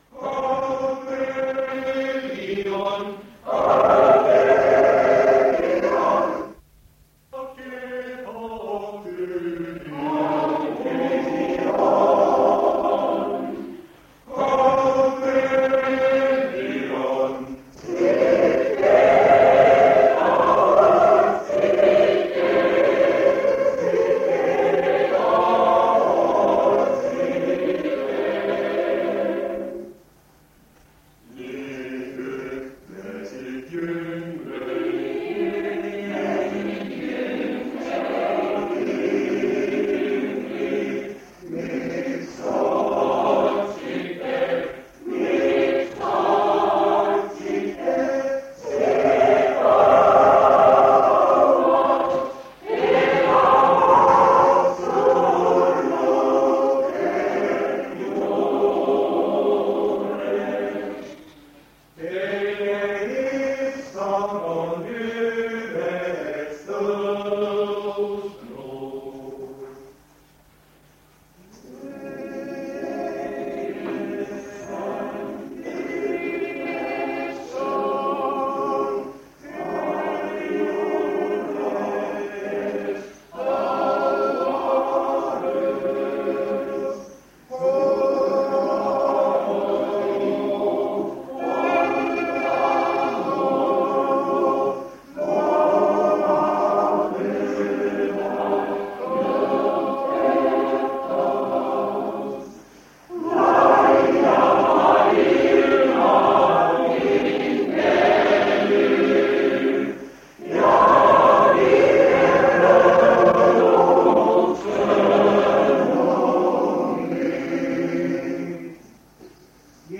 Koosoleku kava Tallinna koor - Haud tühi on Meeskoor - Maised rõõmud mind ei köida.. Segakoor - Jeesus minu kallis veri.. JUTLUS Segakoor - Halleluuja... Keelpillid - INSTR.
Noortekoor - Mu kodutee üles Taevasse läheb Noortekoor - Jeesus, võta mu käed Tal
Koosolekute helisalvestused
Koosolek vanalt lintmaki lindilt aastast 1977.
On ÜLESTÕUSMISE TEENISTUS